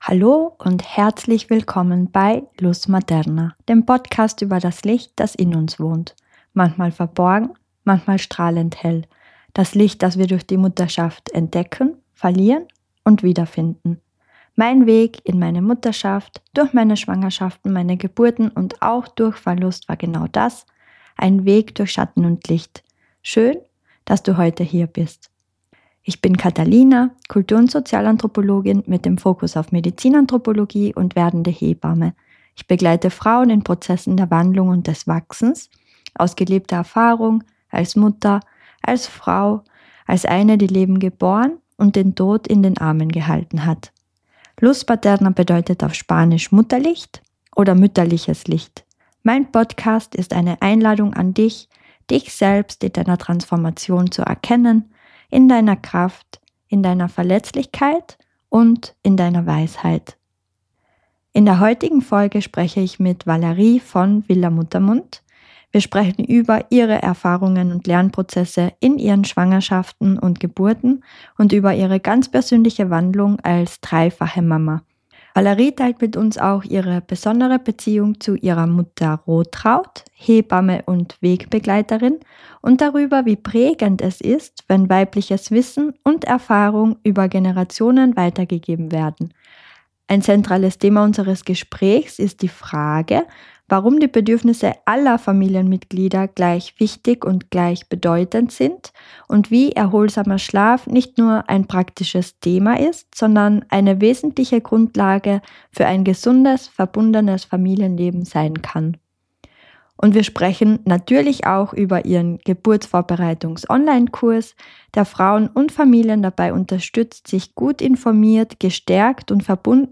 Dieses Gespräch ist ehrlich, nährend, stellenweise sehr lustig – und geprägt von jener stillen Verständigung zwischen zwei Frauen, die oft schon wissen, was die andere meint, noch bevor sie es ausgesprochen hat.